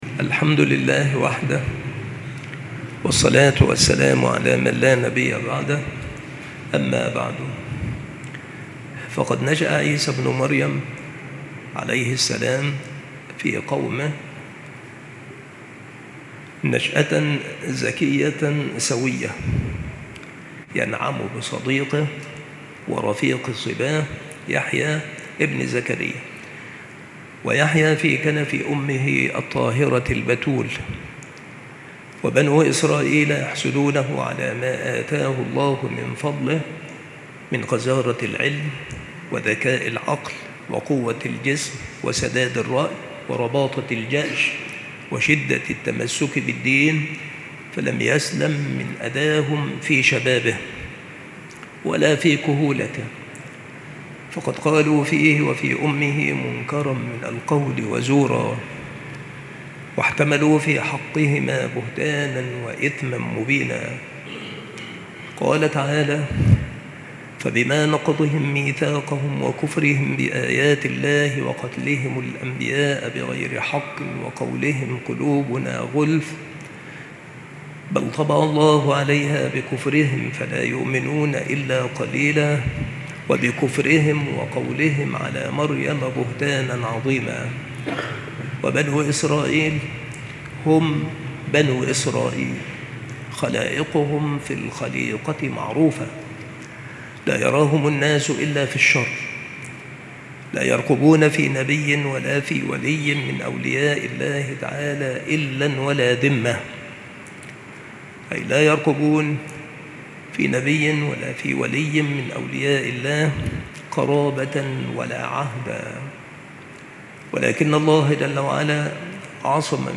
التصنيف قصص الأنبياء
مكان إلقاء هذه المحاضرة بالمسجد الشرقي - سبك الأحد - أشمون - محافظة المنوفية - مصر